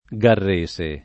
[ g arr %S e ]